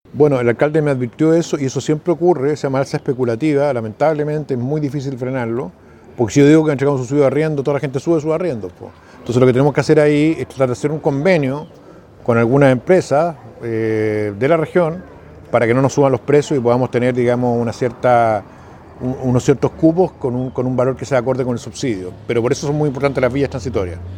Consultado el futuro ministro de Vivienda en su paso por la zona, Iván Poduje, dijo que espera que las empresas relacionadas con el rubro de la construcción y suministros básicos moderen sus alzas.